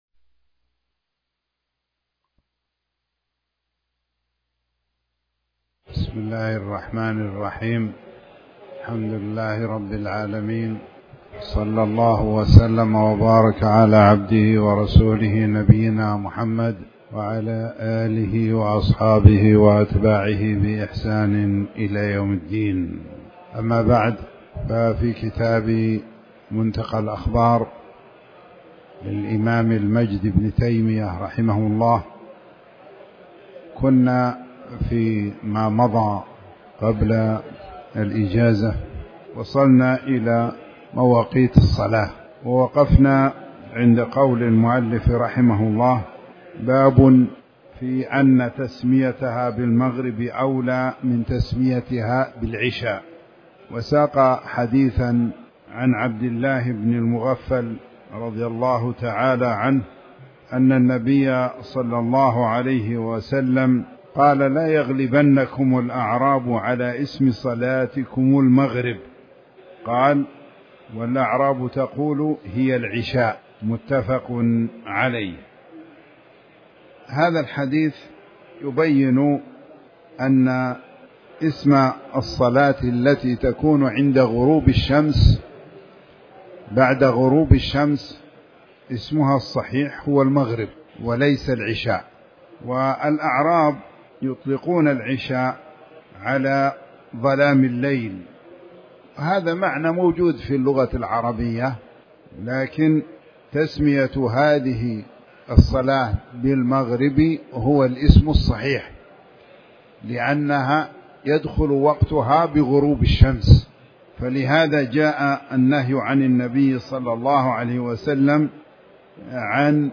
تاريخ النشر ١ محرم ١٤٤٠ هـ المكان: المسجد الحرام الشيخ